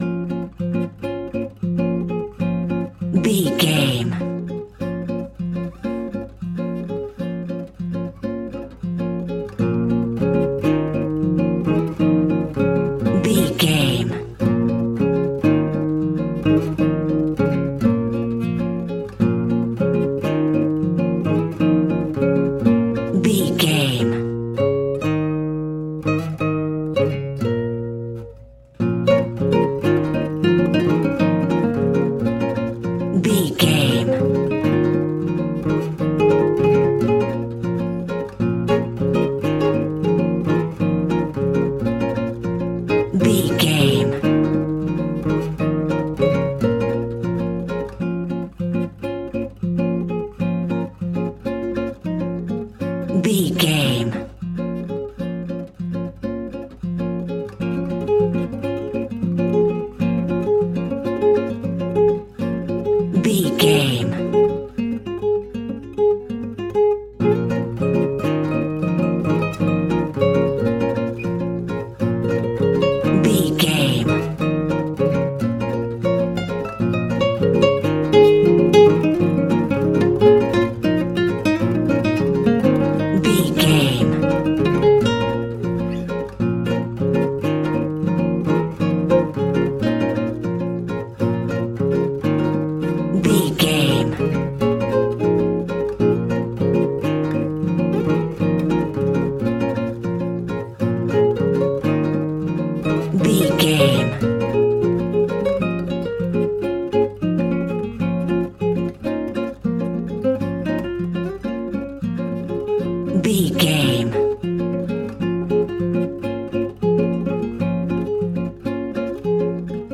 Aeolian/Minor
B♭
maracas
percussion spanish guitar